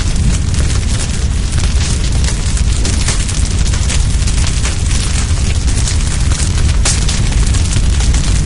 fire_large.ogg